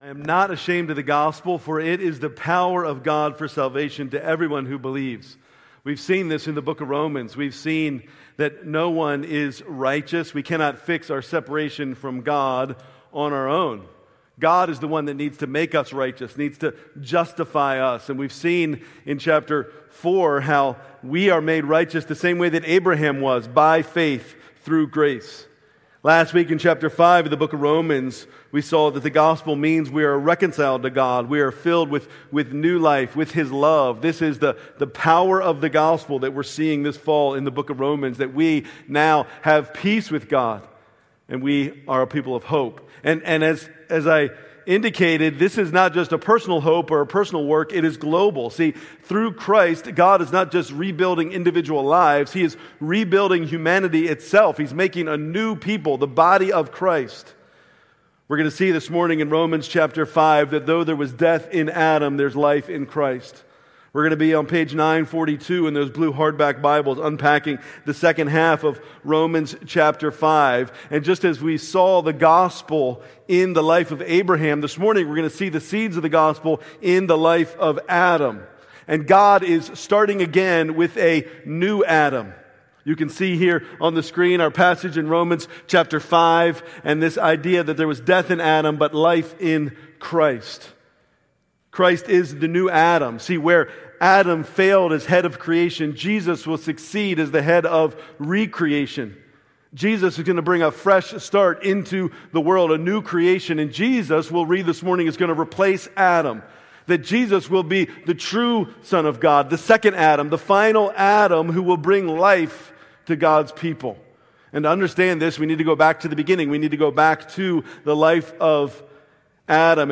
November 16, 2005 Worship Service Order of Service: